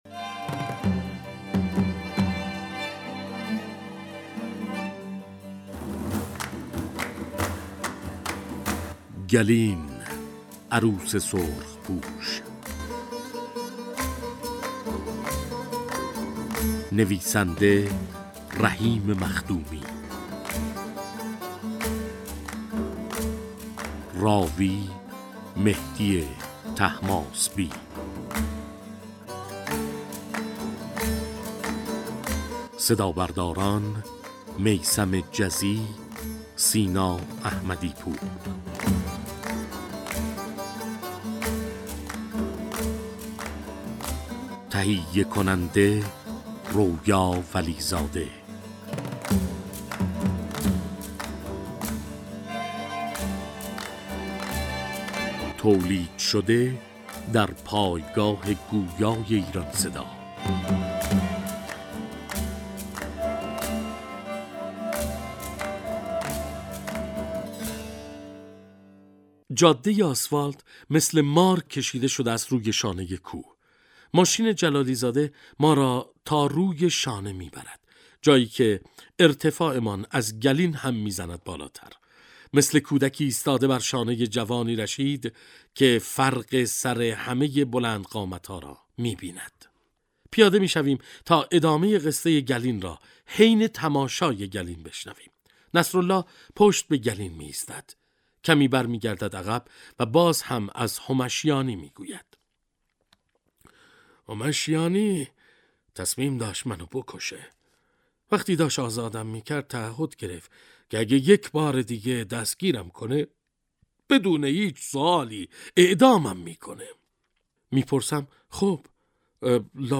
«گلین» نام روستایی در حوالی سنندج است که سال 1360 حوادث تلخ و خونباری را تجربه می کند و گوشه ای از تاریخ رشادت های مردمان کردستان را در دل خود جای داده است. این کتاب توسط «ایران صدا» به کتاب گویا تبدیل شده و نسخه صوتی آن برای مخاطبان نوید شاهد در ادامه مطلب قابل دسترس است.